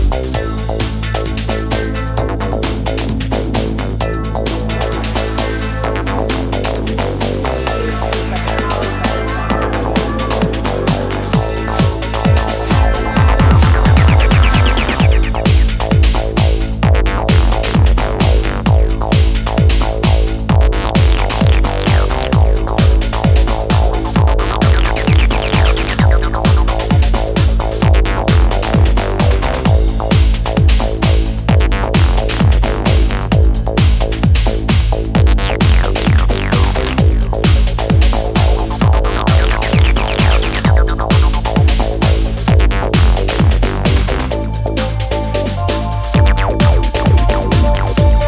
perfectly melds house, trance and techno.